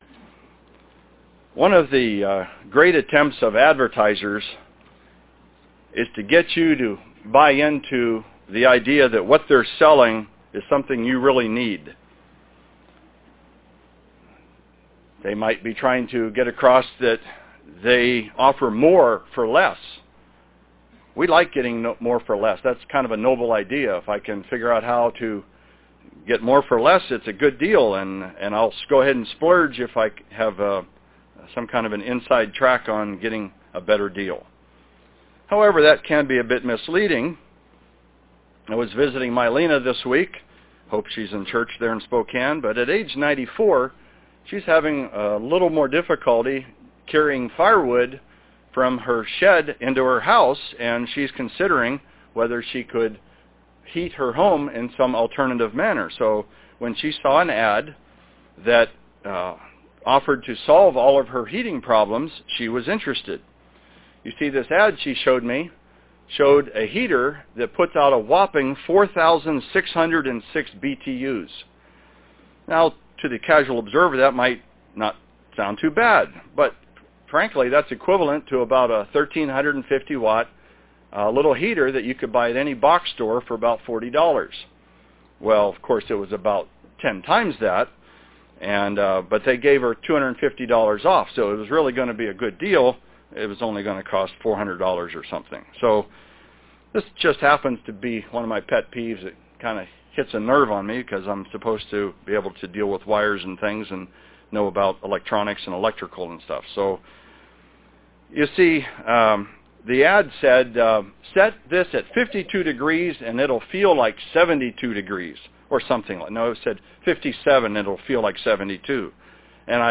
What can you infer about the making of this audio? Given in Spokane, WA